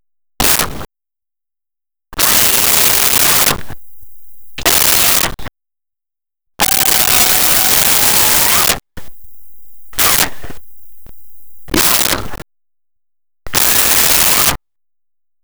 Yelling Female Karate Action
Yelling Female Karate Action.wav